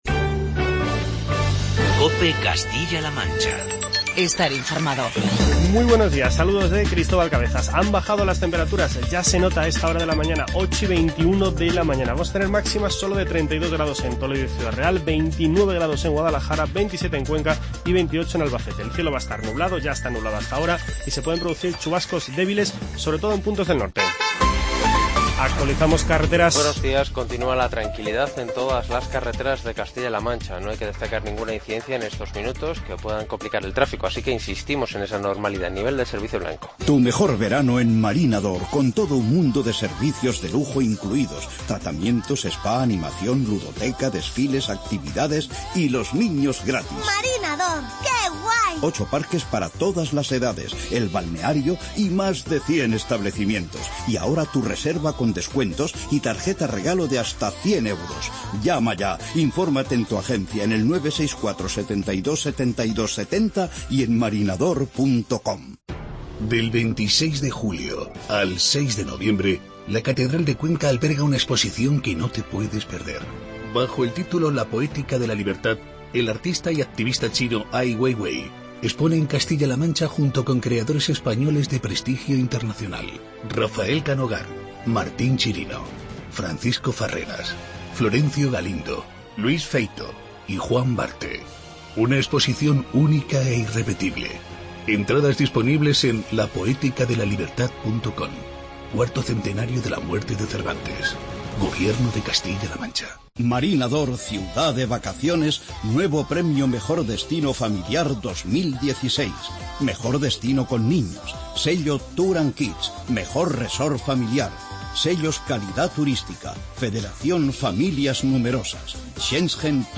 Informativo regional
Escuche las palabras de los diputados de PP y PSOE, Carlos Velázquez y Rosario García Saco.